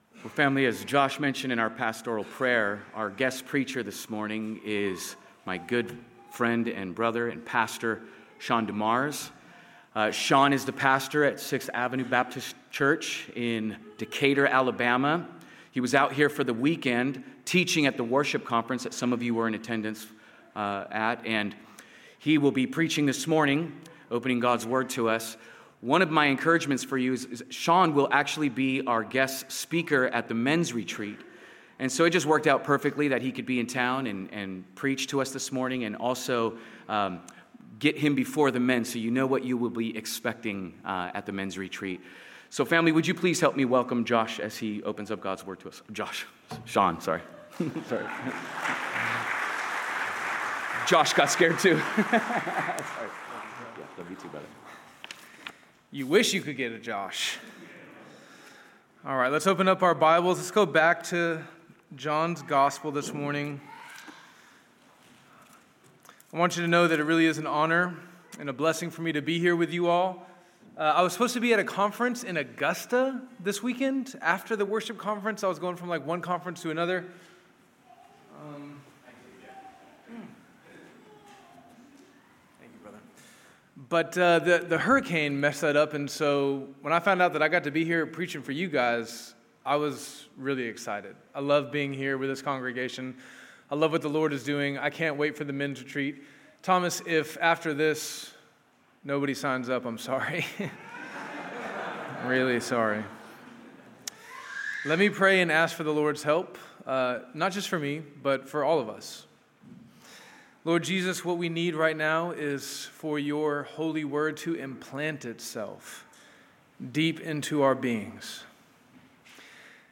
Welcome to the sermon podcast of Trinity Church of Portland Oregon.